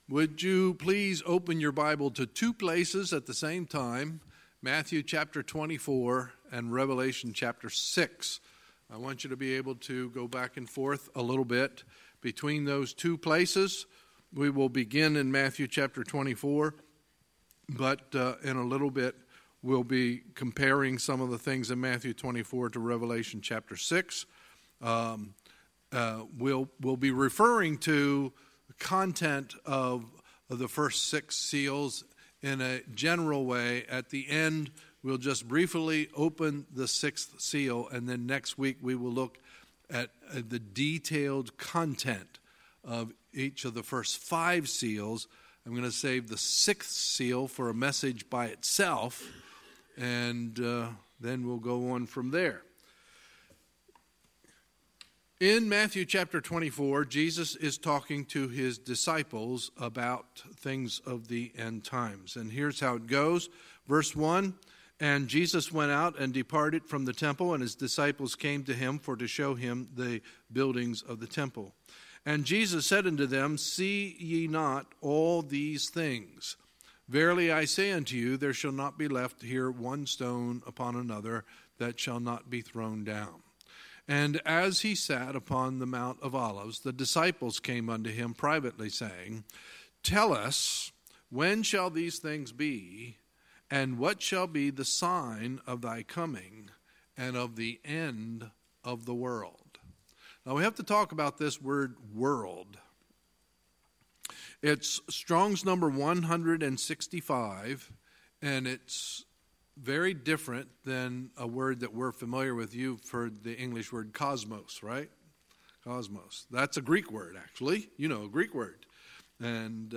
Sunday, November 11, 2018 – Sunday Evening Service